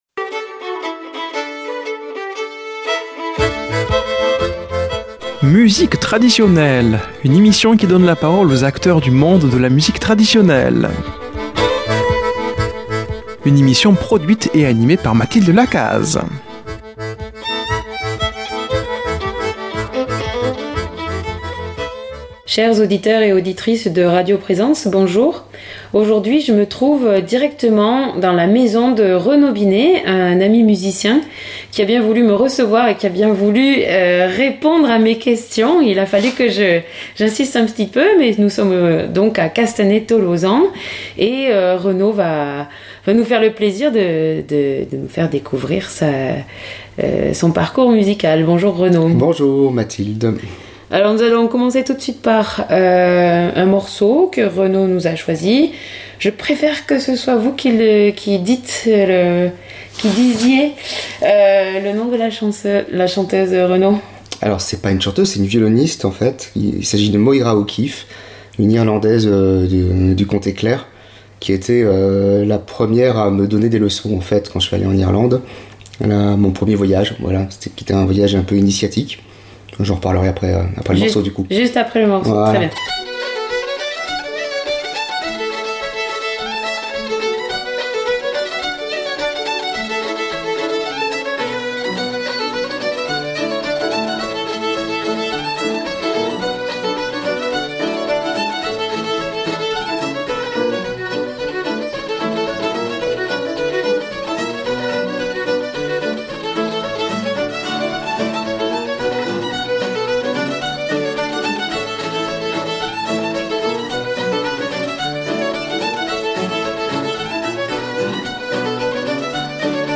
Musique Traditionnelle